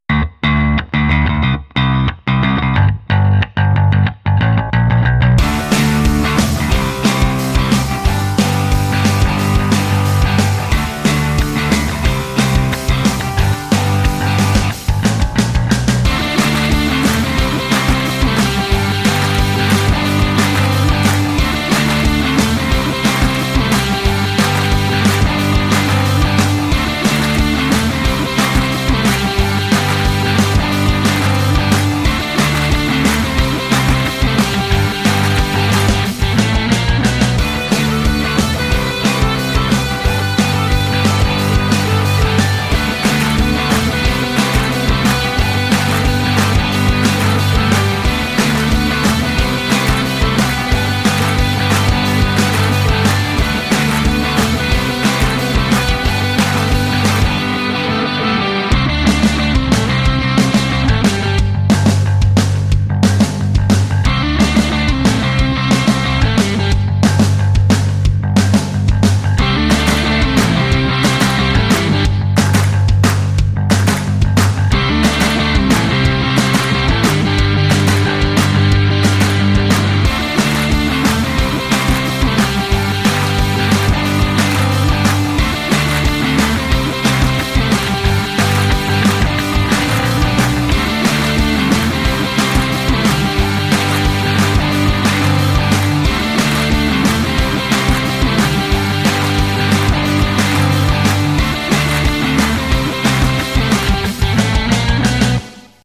This can be useful if you want to drop the vocal track and retain only the music or perform some remix modifications. Here is the original.